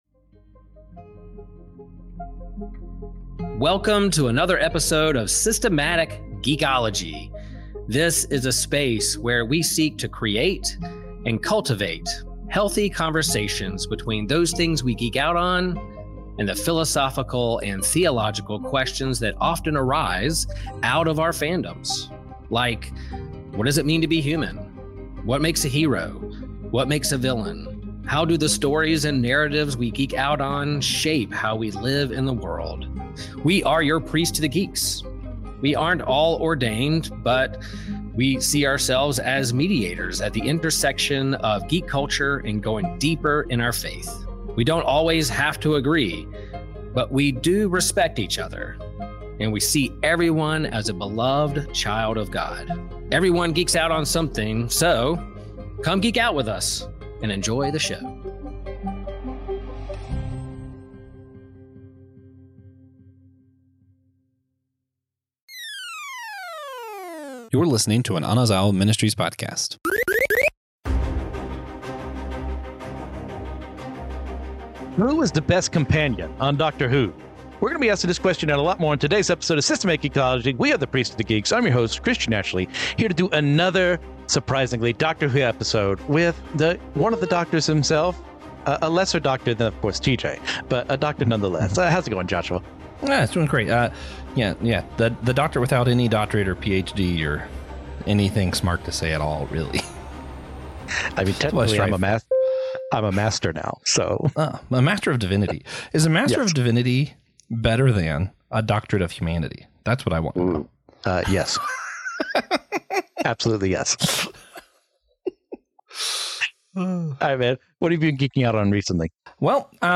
With humor and insightful banter flowing freely, listeners are in for a thought-provoking ride through time, narrative, and the essence of storytelling itself.
The hosts' banter keeps the atmosphere light, though they tackle serious philosophical questions that resonate